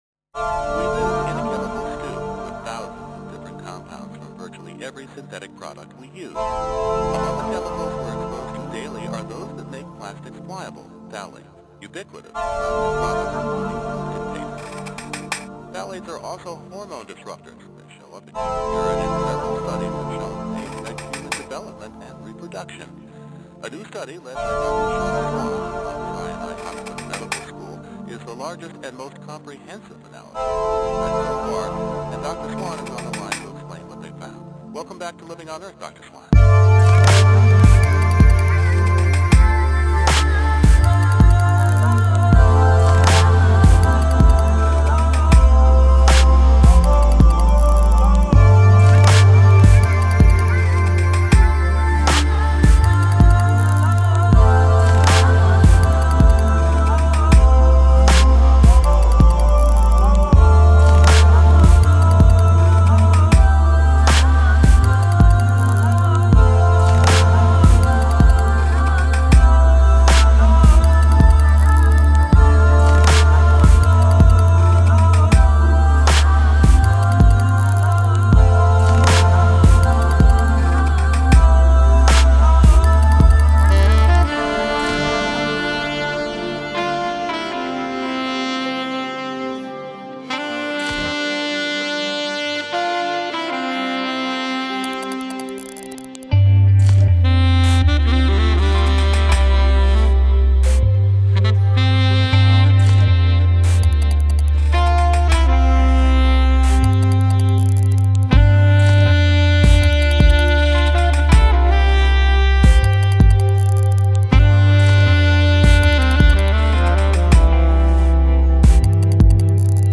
ساکسفون
پیانو